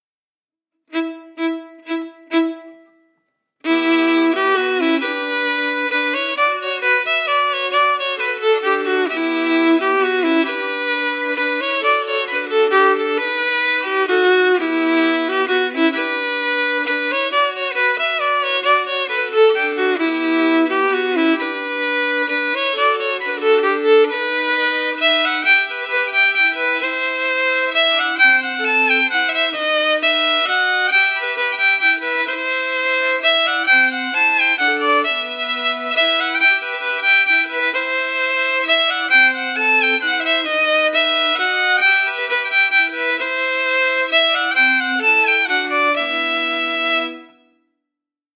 Key: Em
Form: Bourrée a  trois temps (in 3/8)
Melody emphasis
Region: France